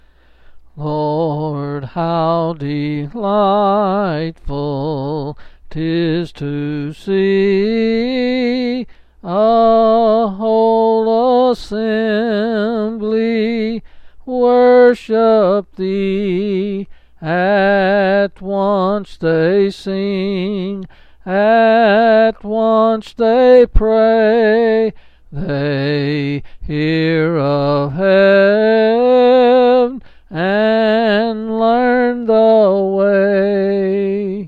Quill Pin Selected Hymn
Sessions. L. M.